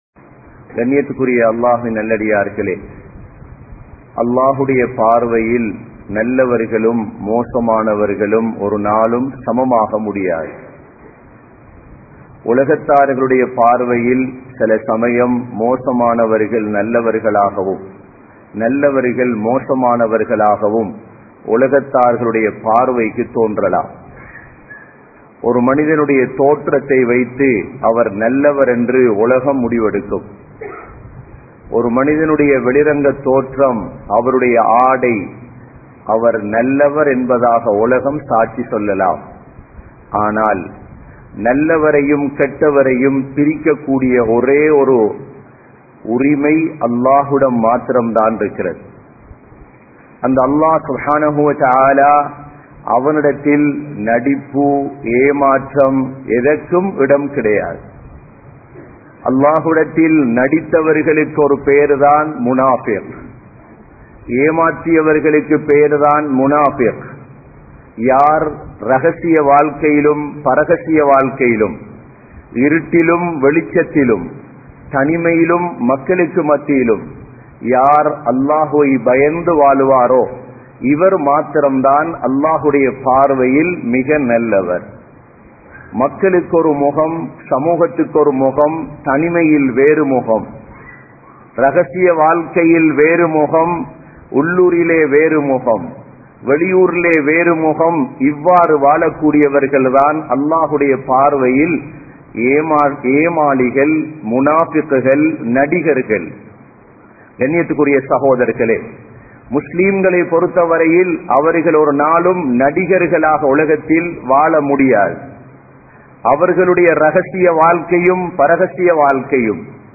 Manitharhalil 03 Vahaienar (மனிதர்களில் 03 வகையினர்) | Audio Bayans | All Ceylon Muslim Youth Community | Addalaichenai